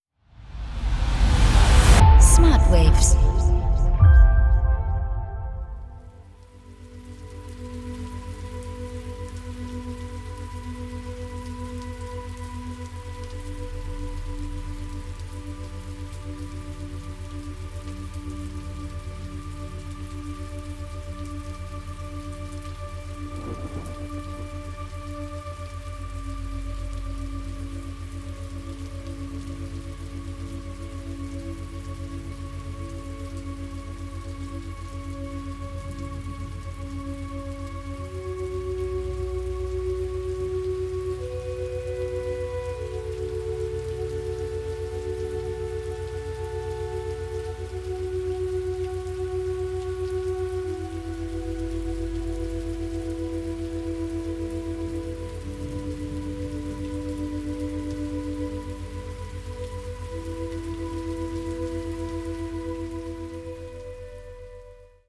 entspannende und schwebende Umgebungsgeräusche
• Methode: Binaurale Beats